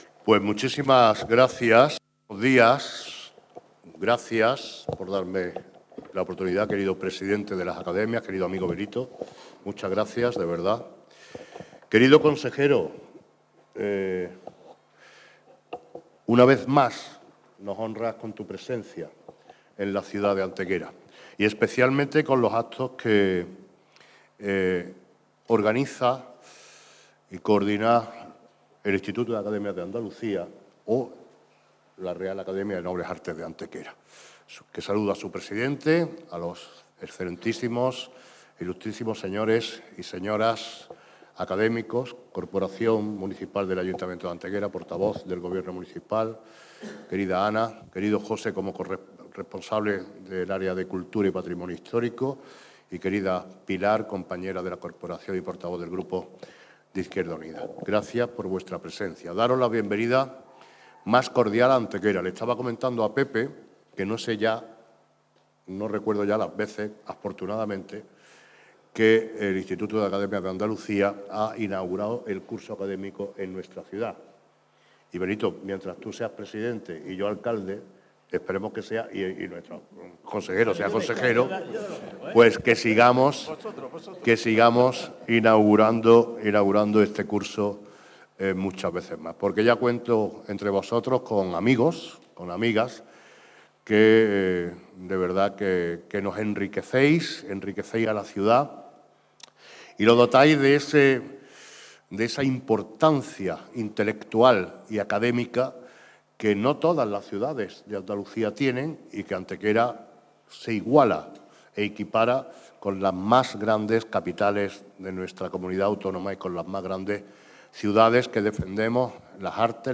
El alcalde de Antequera, Manolo Barón, y el consejero de Universidad, Investigación e Innovación de la Junta de Andalucía, José Carlos Gómez Villamando, han presidido en la mañana de este sábado 23 de noviembre el acto de apertura oficial del curso 2024-2025 del Instituto de Academias de Andalucía, desarrollado en el Salón de Plenos del Ayuntamiento.
Cortes de voz